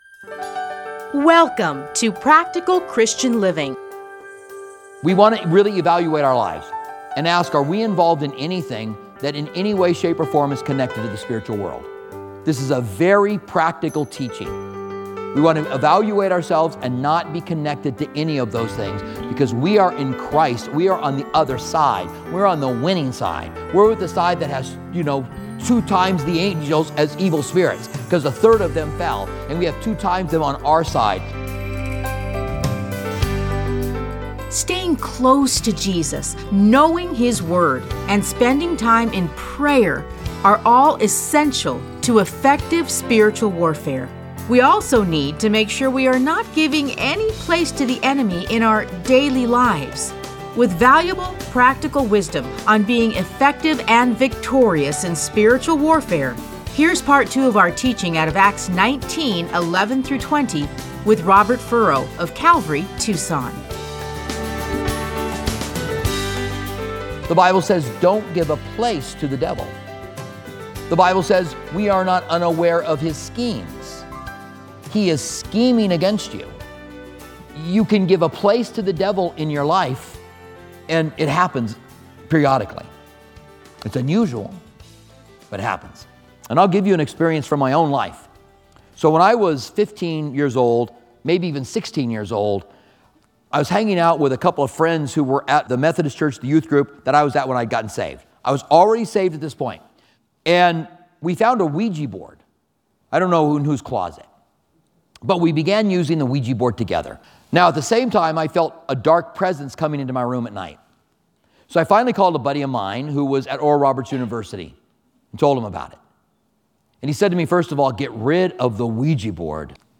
Listen to a teaching from Acts 19:11-20.